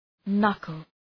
Προφορά
{‘nʌkəl}
knuckle.mp3